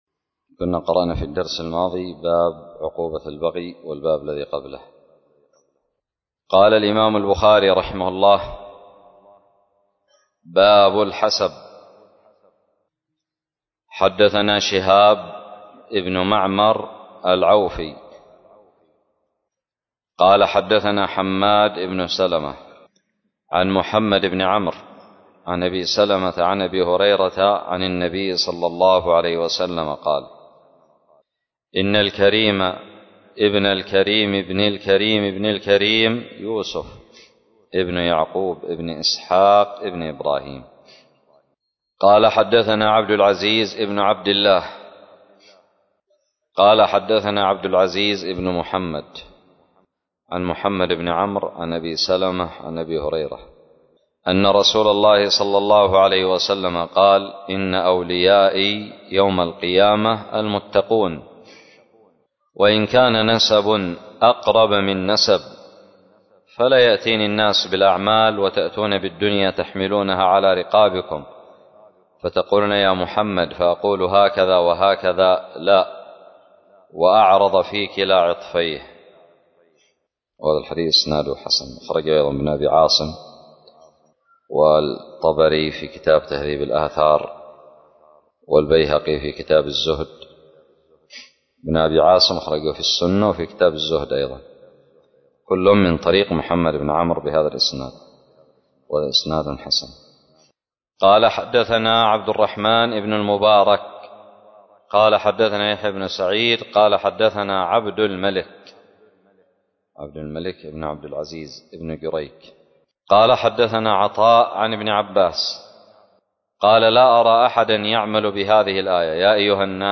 شرح كتاب الأدب المفرد للإمام البخاري -متجدد
ألقيت بدار الحديث السلفية للعلوم الشرعية بالضالع